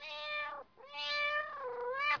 دانلود صدای حیوانات جنگلی 22 از ساعد نیوز با لینک مستقیم و کیفیت بالا
جلوه های صوتی